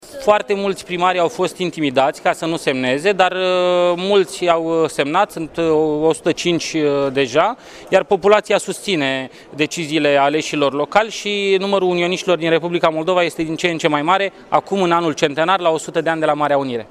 Demersul este susținut și de organizaţia Acțiunea 2012 al cărei președinte este George Simion. Acesta a spus că actuala generație trebuie să întreprindă toate demersurile pentru unirea Republicii Moldova cu România: